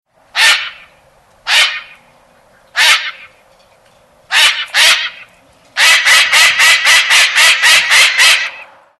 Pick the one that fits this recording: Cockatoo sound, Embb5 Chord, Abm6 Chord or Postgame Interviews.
Cockatoo sound